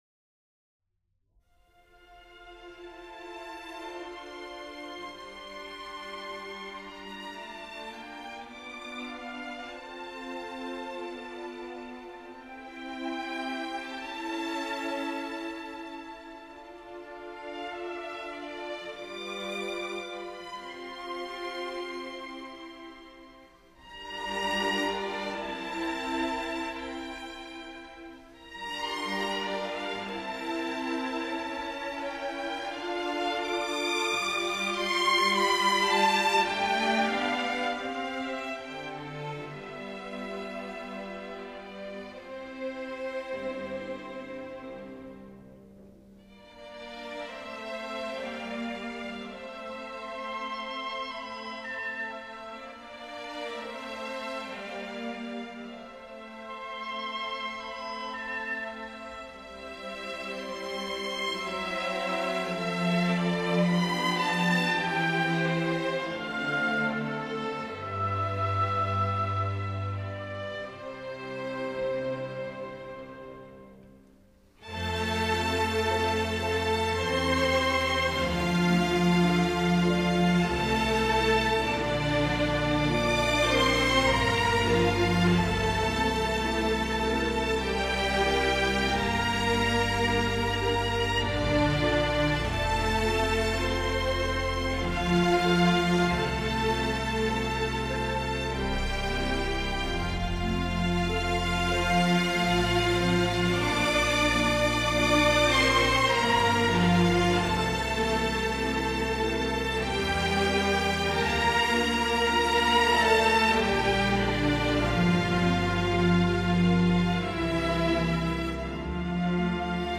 间奏曲